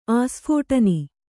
♪ āsphōṭani